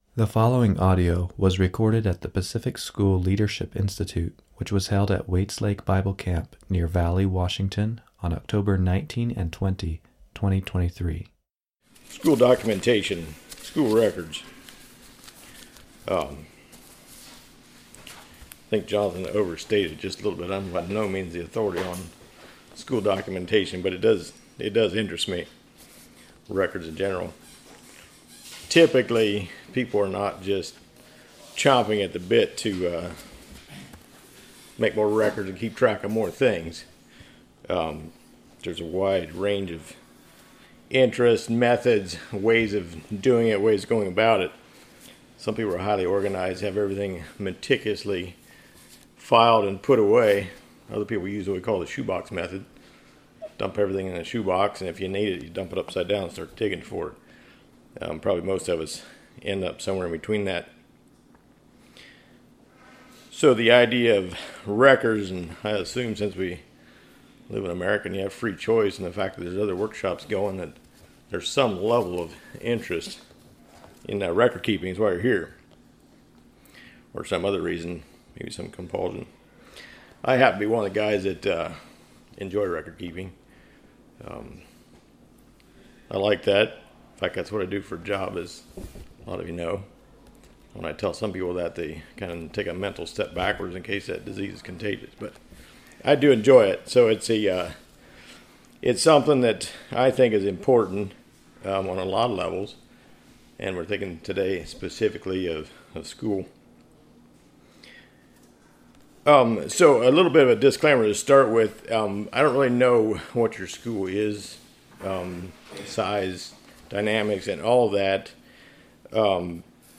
Home » Lectures » Documentation: Keeping Good School Records